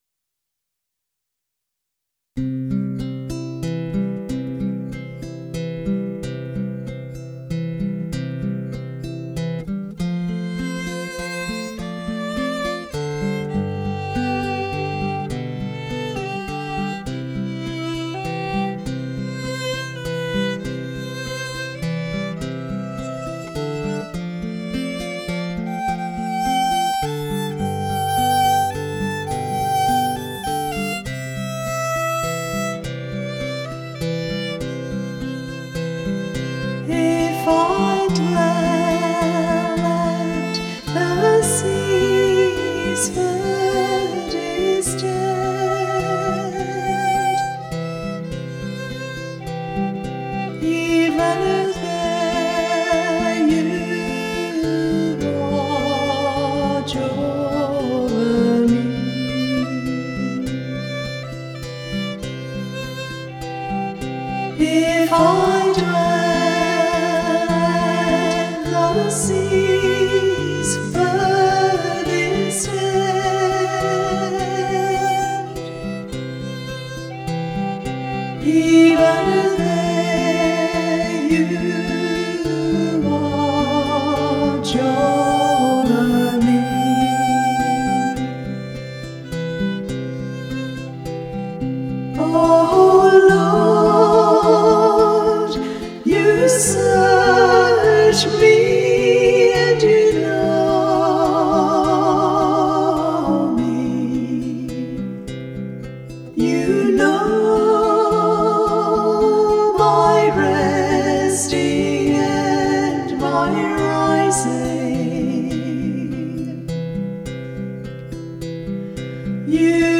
Responsorial Psalm 138(139):1-3,7-10,13-14